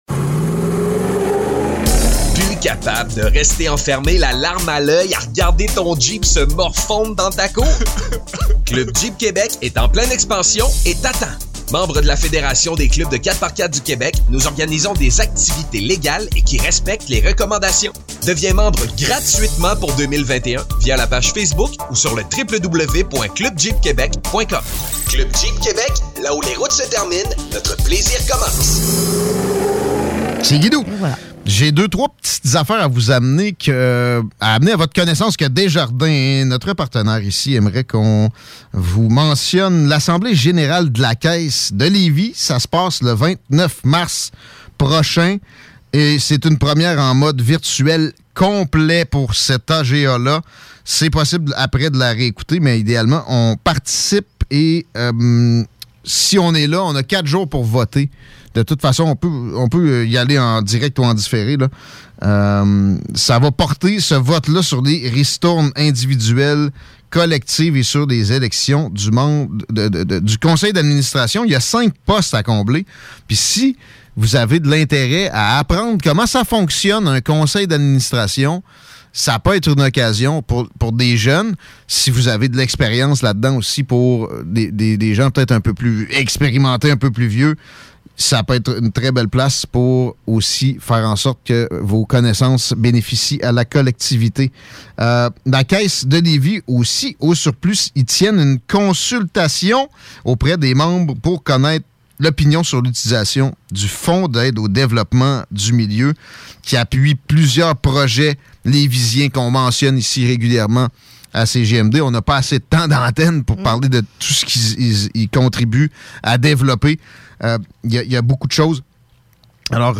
C'est l'actualité décomplexée!! Avec des collaborateurs et collaboratrices colorés et uniques.